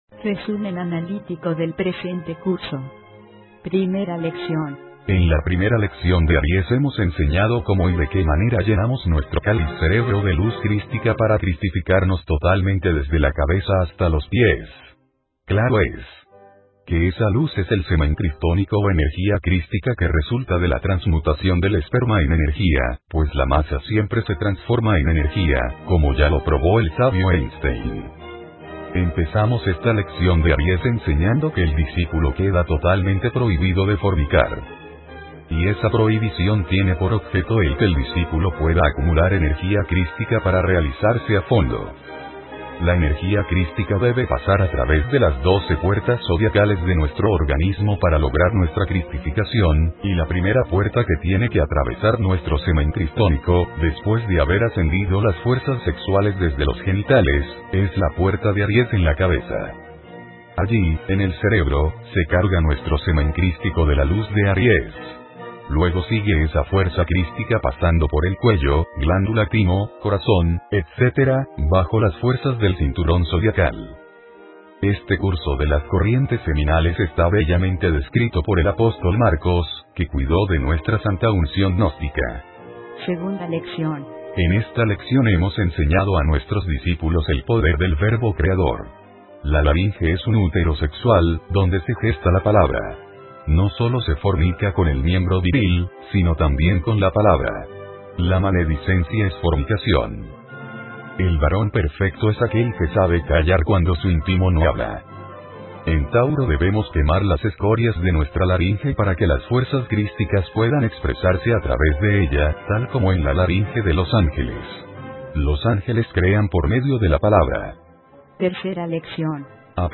Audiolibros del maestro Samael Aun Weor